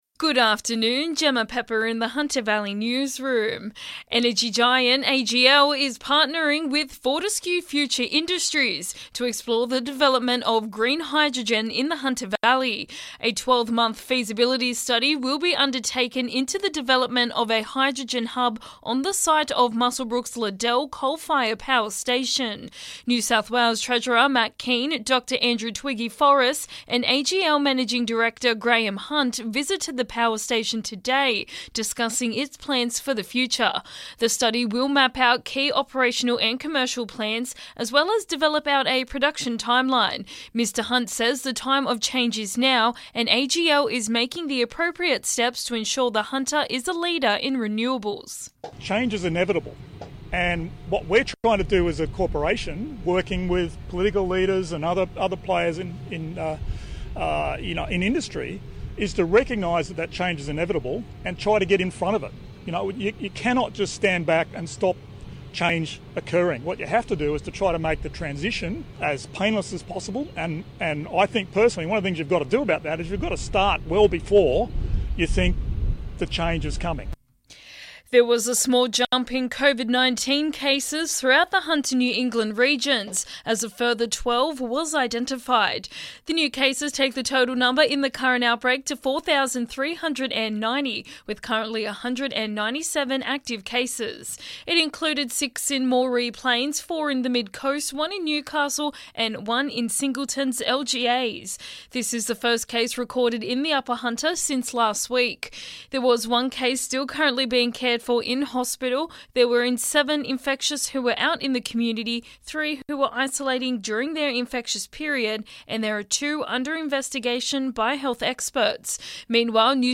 LISTEN: Hunter Valley Local News Headlines 8/12/21